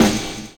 Snare (23).wav